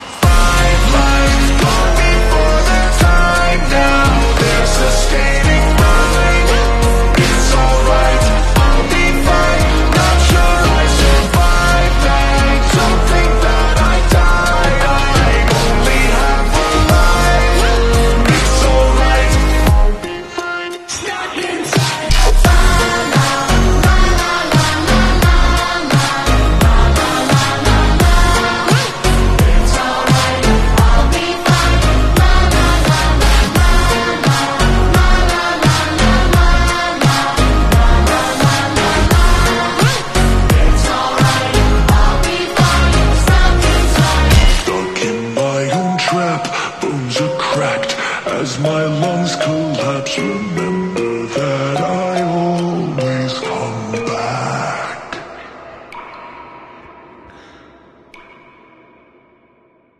💪 Watch it show how real off-roading is done at the Mud 4 Fun 4X4 Challenge!